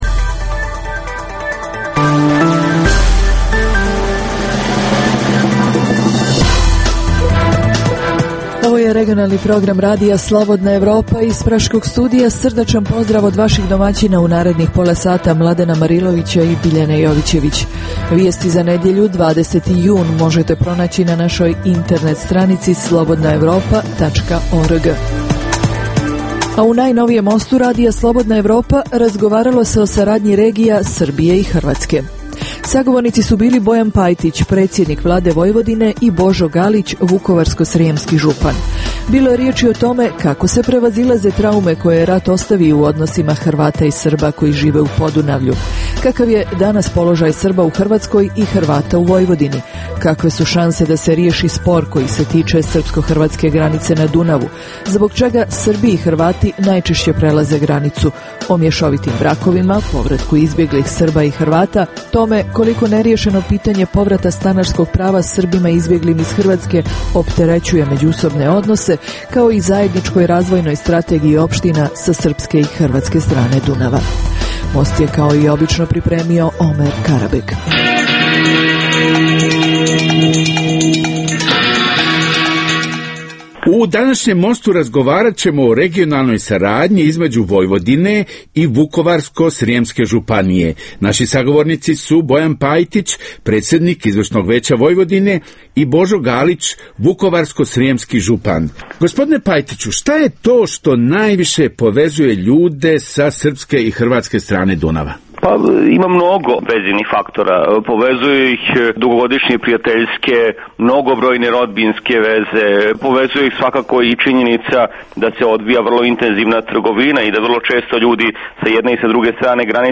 U najnovijem Mostu RSE razgovaralao se o saradnji regija Srbije i Hrvatske. Sagovornicu su bili Bojan Pajtić, predsjednik Vlade Vojvodine i, Božo Galić, vukovarsko-srijemski župan.